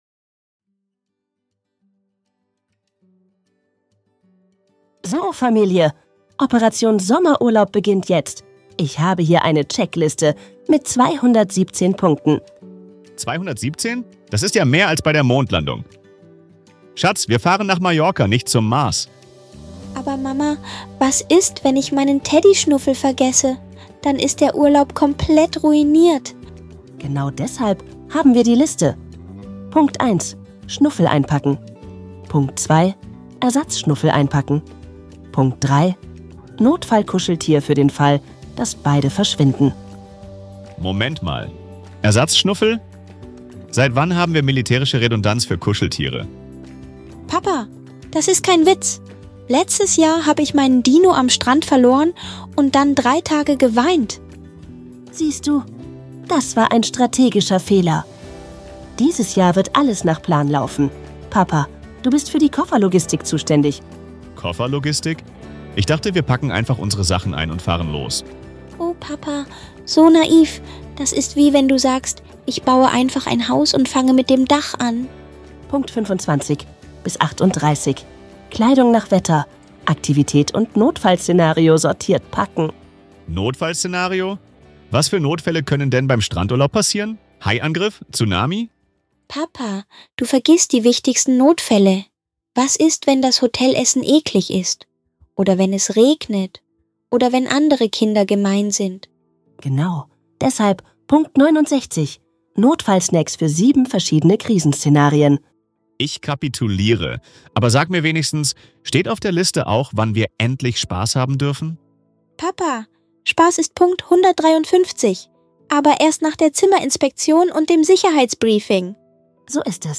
In diesem lustigen Hörspiel erlebt ihr, wie